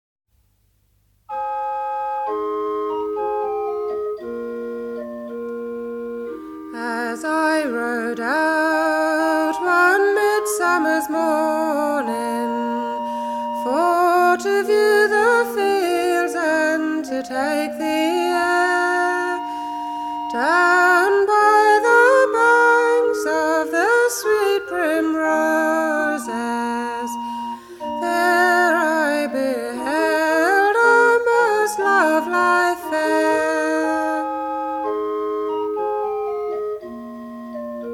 The Royal Oak, Lewes
Bob's 85th birthday party